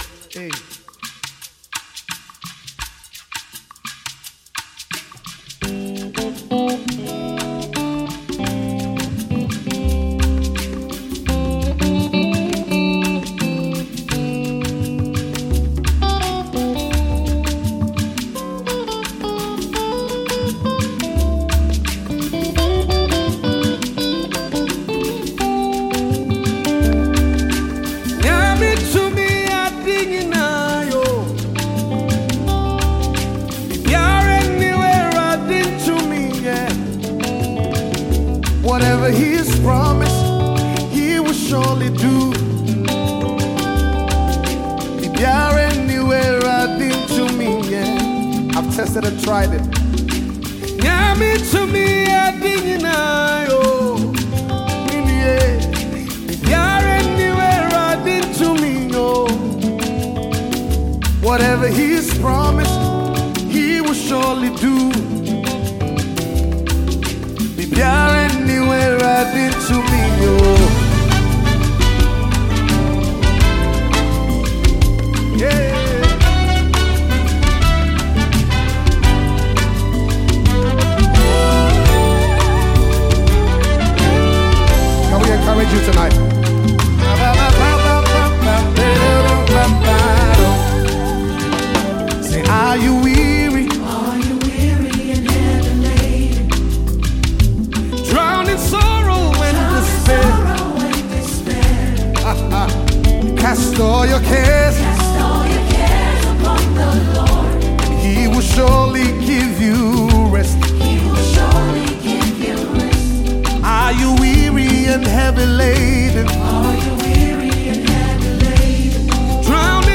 Multiple award-winning Ghanaian gospel icon
faith-filled and powerful single